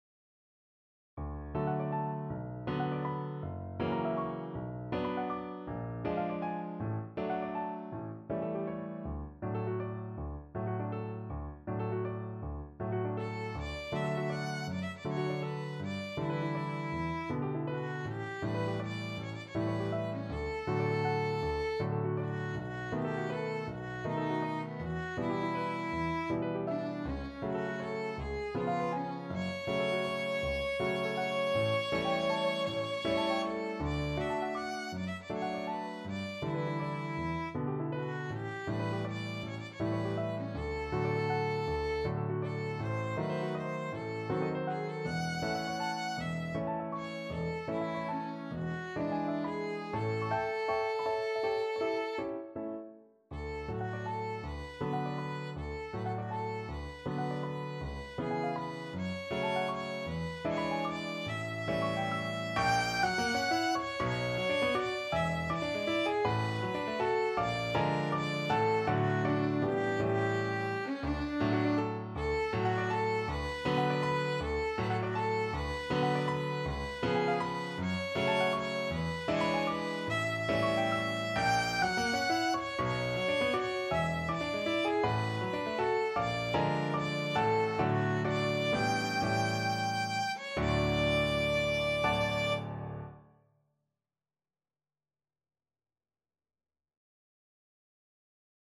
Classical Leoncavallo, Ruggero Mattinata Violin version
Violin
D major (Sounding Pitch) (View more D major Music for Violin )
6/8 (View more 6/8 Music)
Classical (View more Classical Violin Music)